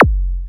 taiko-soft-hitwhistle.ogg